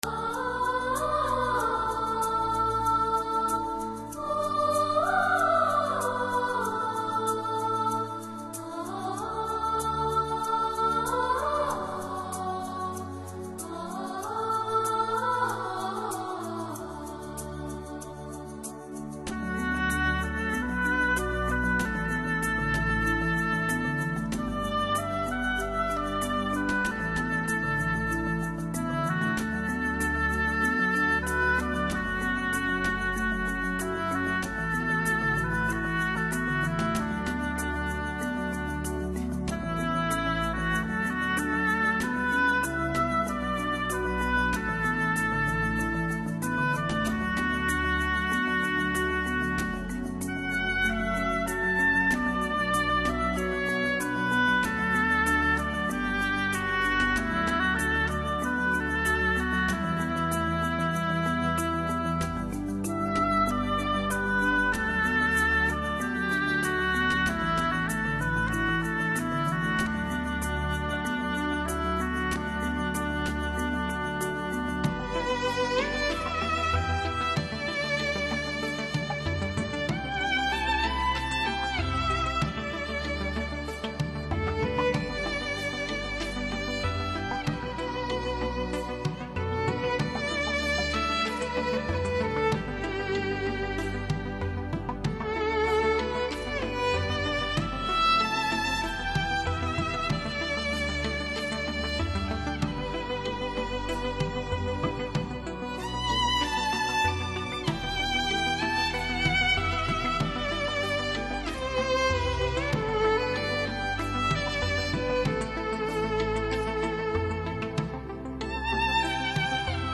[分享]小提琴协奏曲 茉莉花]